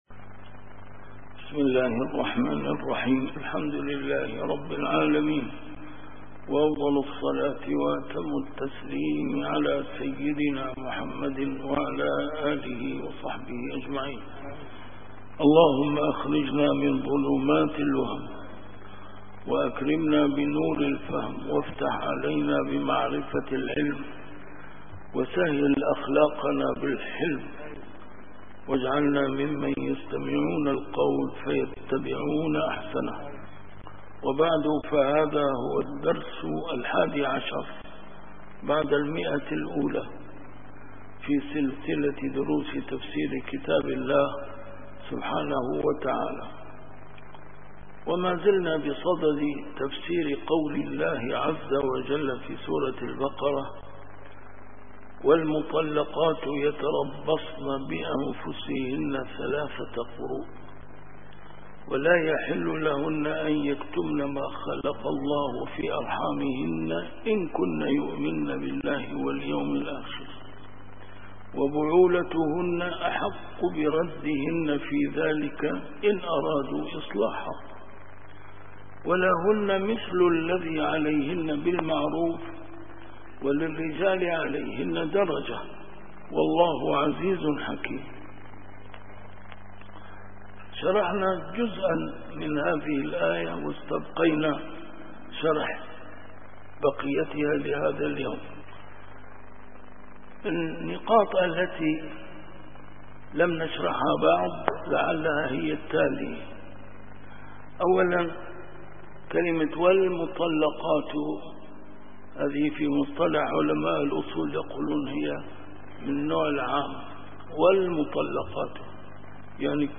A MARTYR SCHOLAR: IMAM MUHAMMAD SAEED RAMADAN AL-BOUTI - الدروس العلمية - تفسير القرآن الكريم - تفسير القرآن الكريم / الدرس الحادي عشر بعد المائة: سورة البقرة: الآية 228